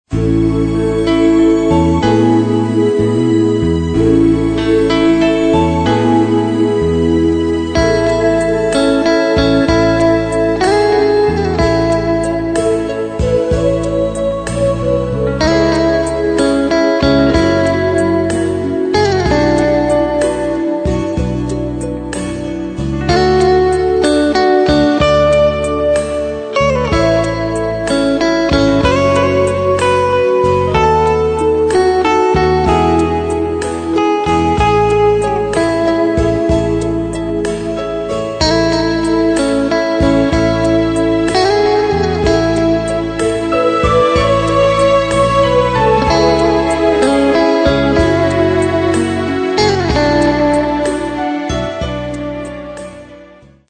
Hintergrundmusik